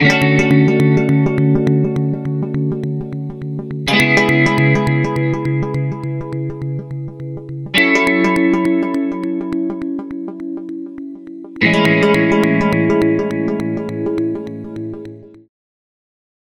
Rhythmic flange effect, similar to an arpeggiator or sequencer, by emphasizing notes through rate and feedback highest settings and 'pulse' waveform active.
Sonicircuit-Stereo-Flanger-12.Step-Sequencer-Ripples-Guitar-Wet.mp3